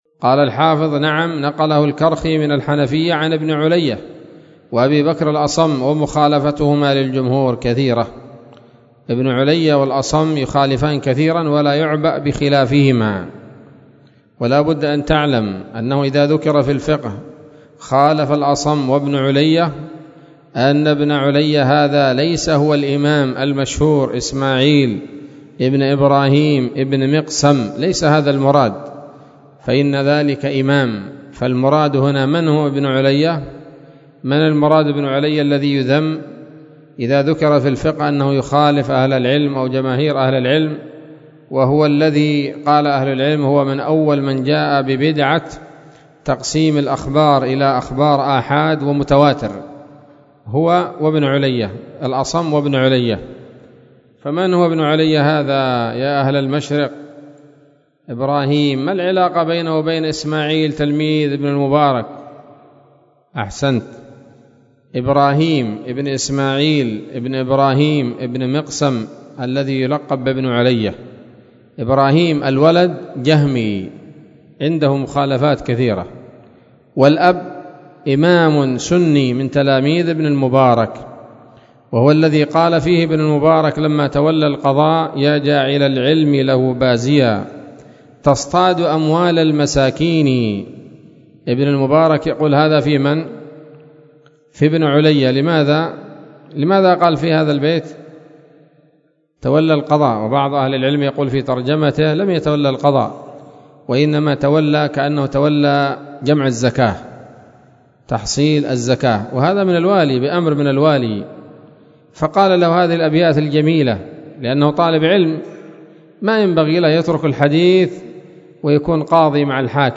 كلمة ونصيحة قيمة بعنوان: (( بعد السلف عن عمل السلاطين )) ليلة الجمعة 07 محرم 1444هـ، بدار الحيث السلفية بصلاح الدين
كلمة ونصيحة قيمة حول بعد السلف عن عمل السلاطين، ضمن درس نيل الأوطار